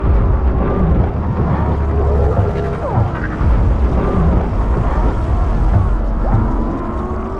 repairloop.wav